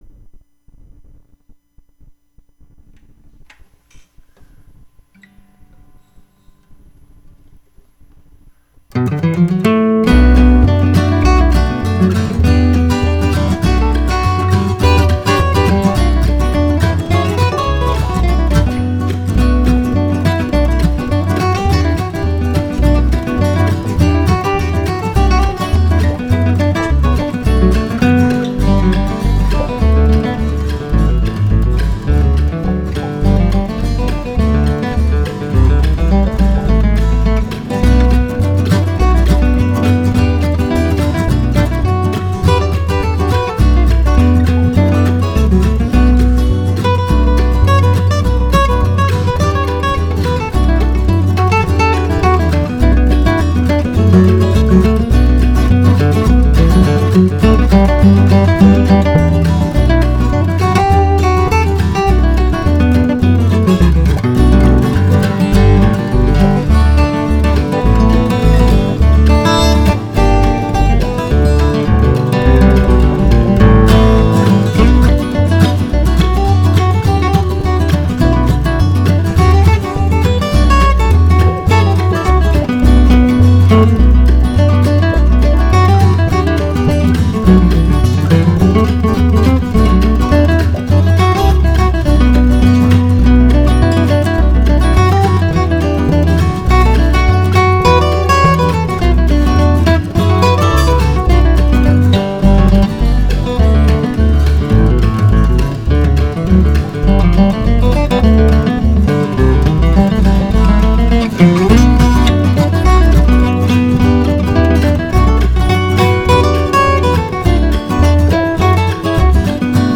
JAZZ GUITAR